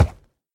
Sound / Minecraft / mob / horse / wood3.ogg
wood3.ogg